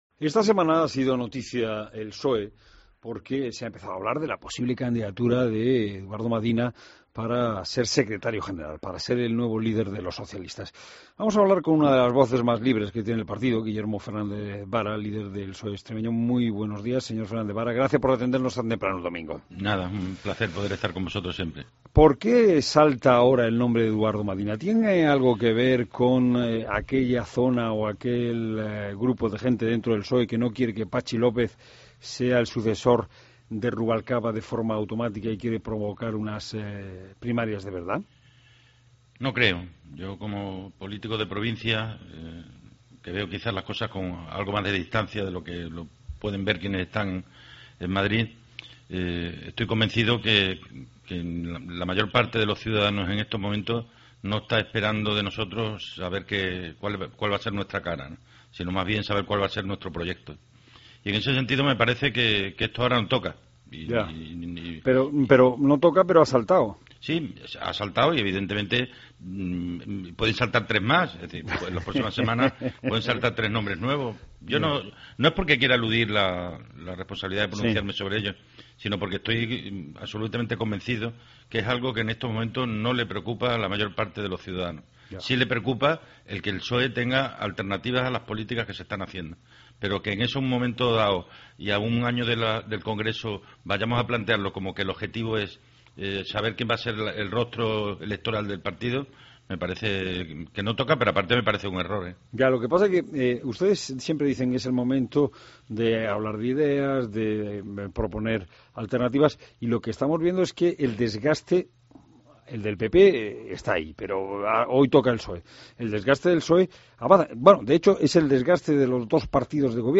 Escucha la entrevista a Fernández Vara en La Mañana del Fin de Semana